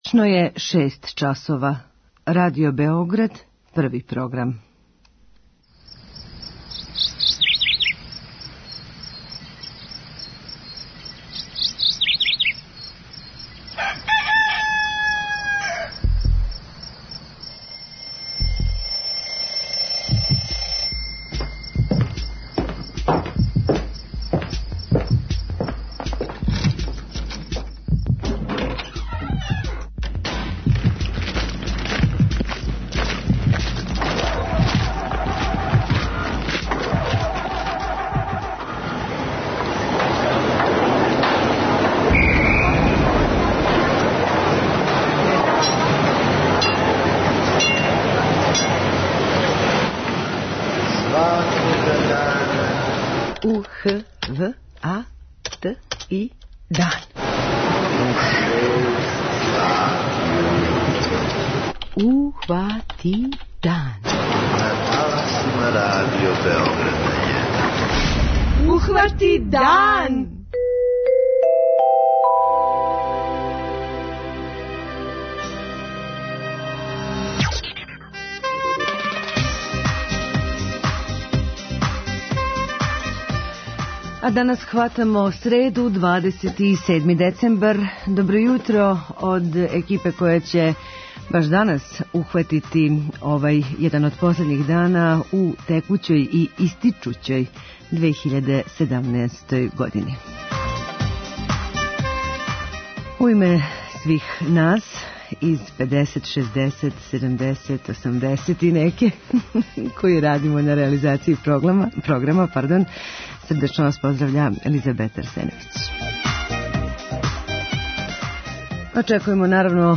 06:03 Јутарњи дневник, 06:35 Догодило се на данашњи дан, 07:00 Вести, 07:05 Добро јутро децо, 08:00 Вести, 08:10 Српски на српском, 08:45 Каменчићи у ципели